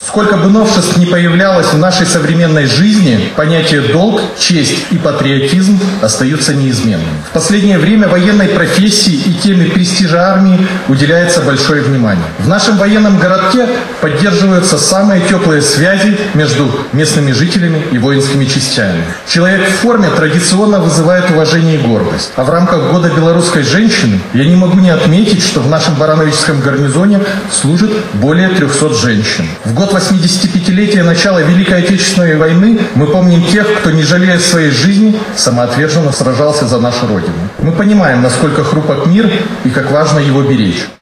На страже мирной жизни. Торжественное мероприятие состоялась в городском Доме культуры Барановичей
Председатель горисполкома Максим Антонюк поблагодарил людей в форме, которые круглосуточно несут службу в нашем регионе.